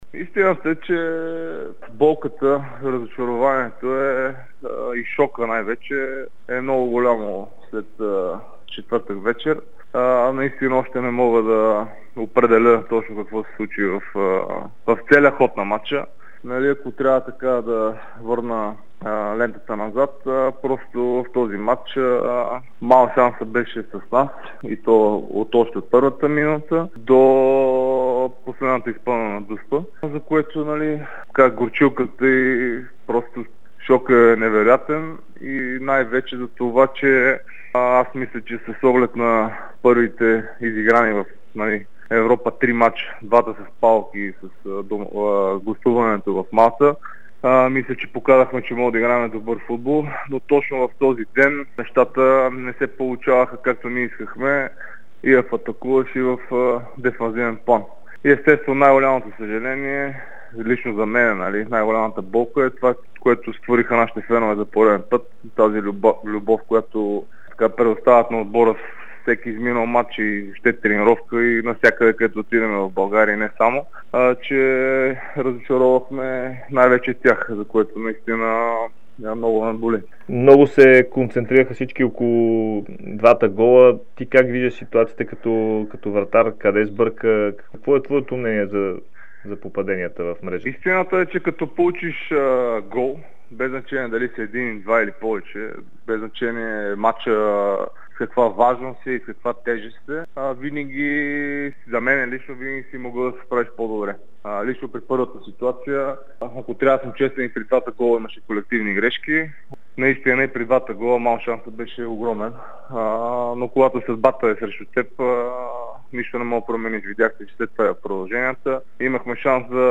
Капитанът на Левски Николай Михайлов говори за първи път пред Дарик радио и dsport след злополучния мач срещу Хамрун от турнира Лига на конференциите. Вратарят коментира грешките, усещането след двубоя и за какво ще се борят „сините“ по време на сезона. Михайлов поднесе своите извинения на феновете и им благодари за безрезервната подкрепа, която те дават на отбора през последните месеци.